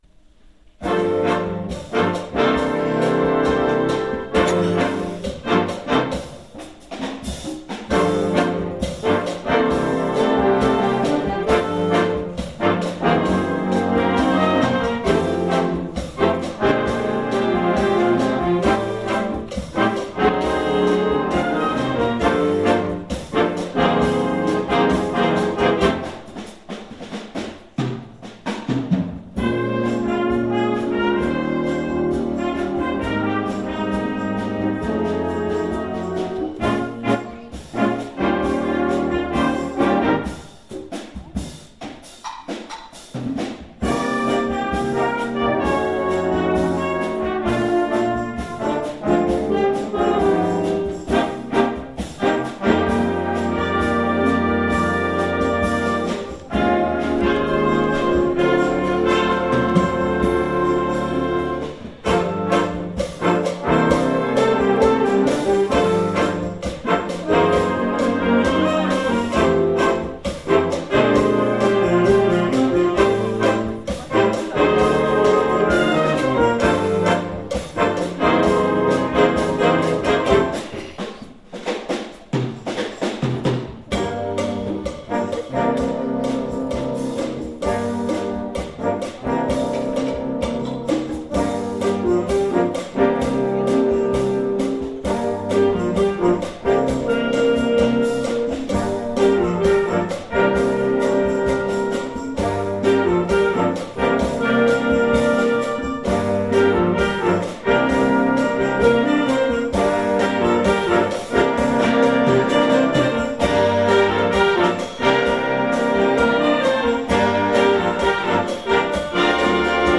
Concert de Noël, St Jean, 11 décembre 2005
Orchestre senior Départemental (OSD)